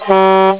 Sons urbanos 35 sons
buzina5.wav